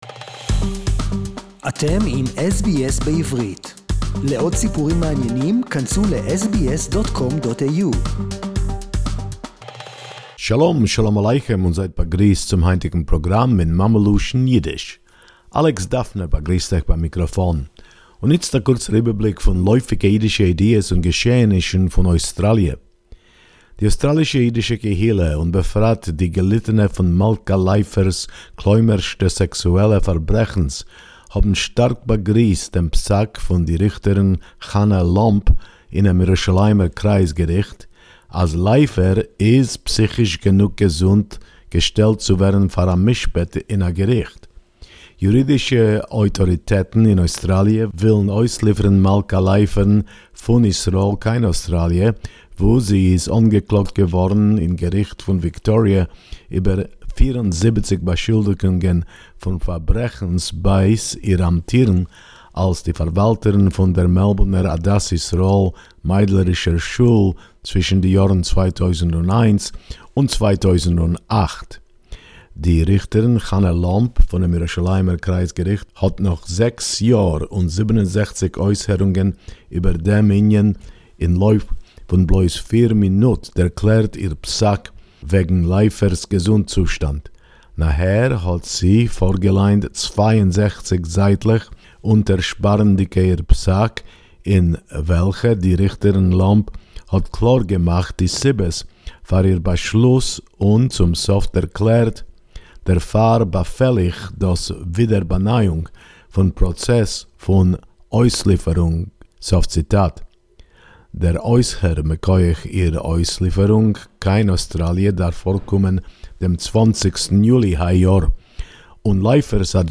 Yiddish report 31.5.2020